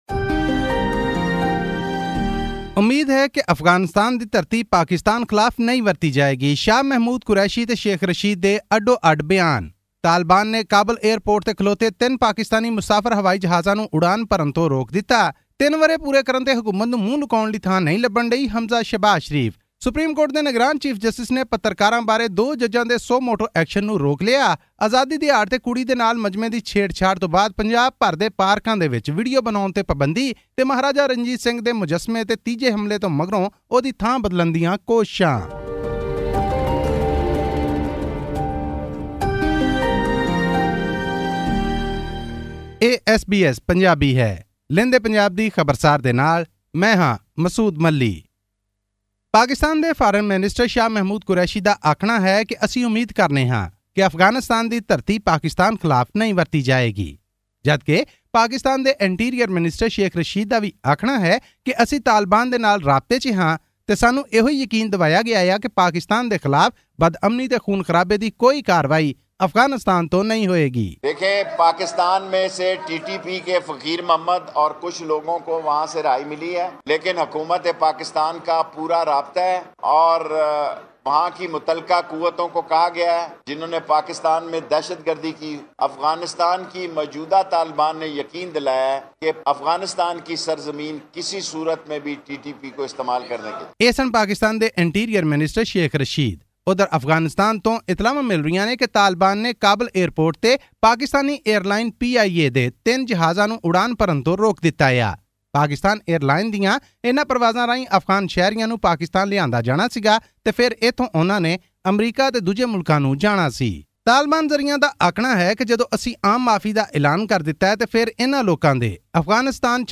A Pakistani court has granted bail to a man who vandalised the statue of Sikh ruler Maharaja Ranjit Singh in Lahore. All this and more in our weekly news bulletin from Pakistan.